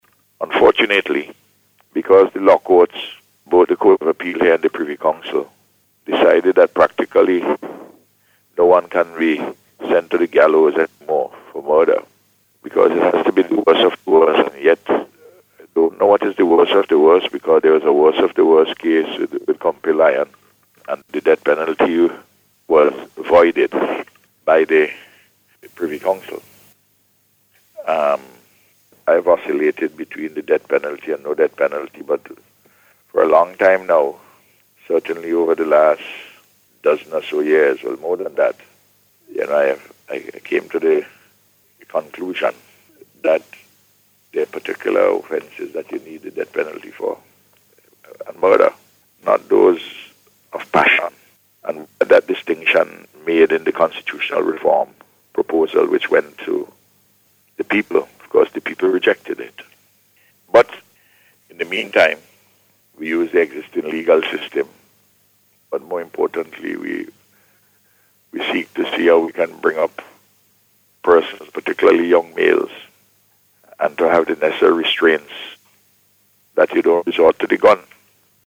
Speaking on NBC Radio yesterday, Prime Minister Gonsalves urged young men to restrain themselves from the fascination of guns.